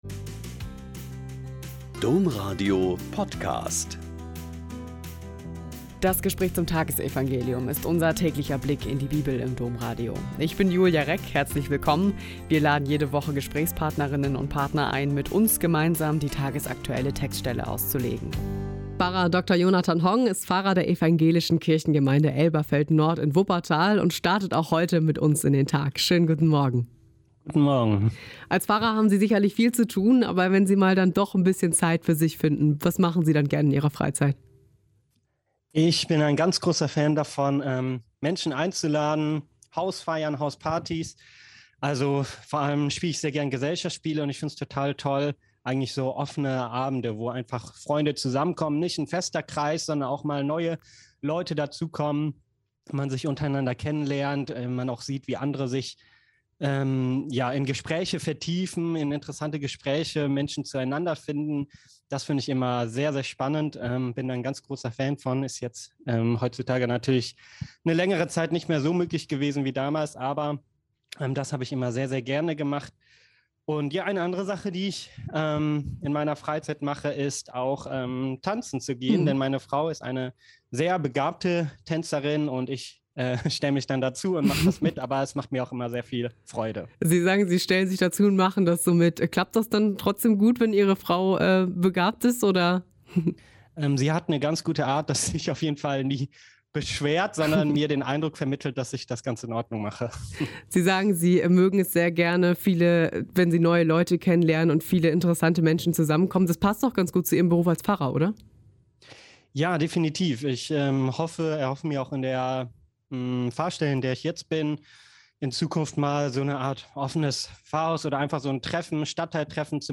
Mk 8,27-33 - Gespräch